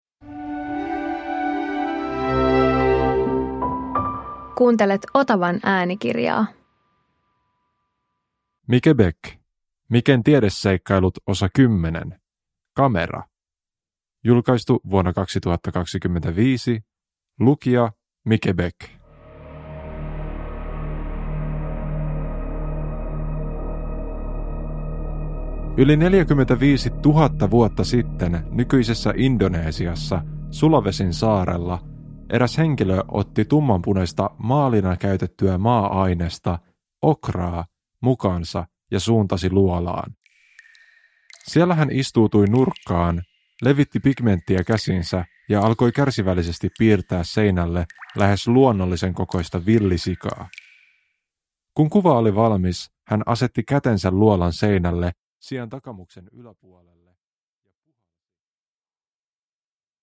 Uppläsare: Mike Bäck
• Ljudbok